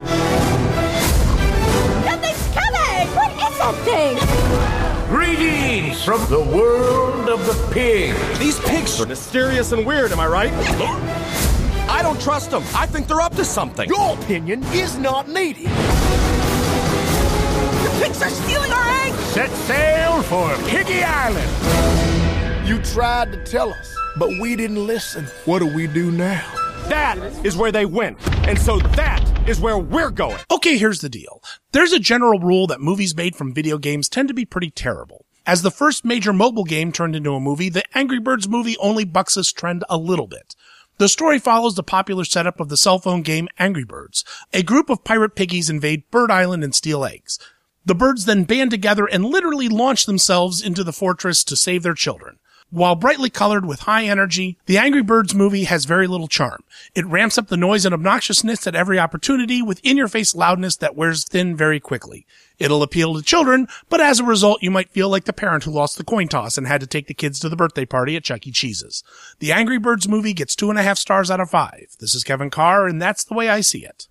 ‘The Angry Birds Movie’ Radio Review